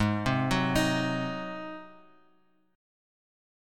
G#7 chord {4 6 4 5 7 4} chord